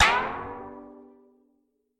Откройте для себя коллекцию звуков нового уровня — инновационные аудиоэффекты, футуристические мелодии и необычные композиции.